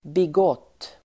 Ladda ner uttalet
Uttal: [big'åt:]